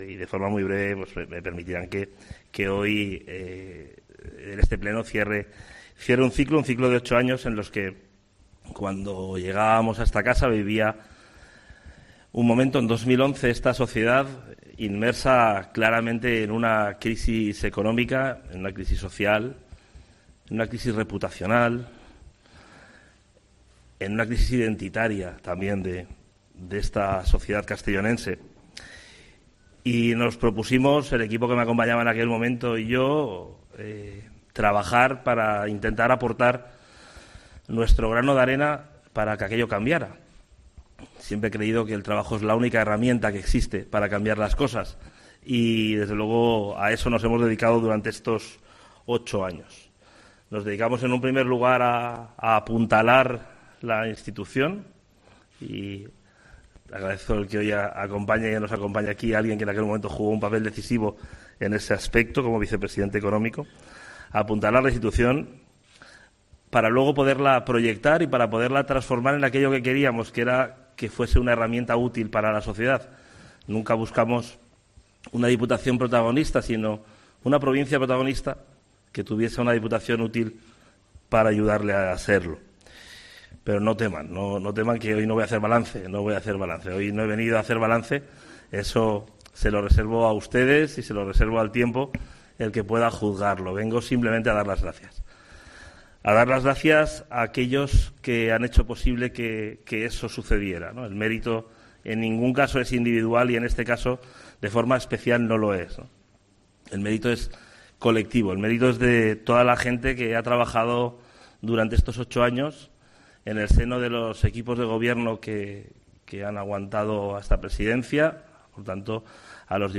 Despedida de Javier Moliner, presidente de la Diputación de Castellón entre 2011 y 2019
Visiblemente emocionado y orgulloso del trabajo desempeñado, rodeando de sus máximos colaboradores y dirigentes del Partido Popular, Javier Moliner se ha despedido de la vida política tras ocho años como presidente de la Diputación de Castellón.
"Me repertirán que en este pleno cierre un ciclo político de ocho años", ha iniciado su intervención en un pleno de aprobación de actas en el que ha querido poner en valor que el trabajo desarrollado por sus equipos de gobierno y también por el resto de diputados provinciales y alcaldes de la provincia.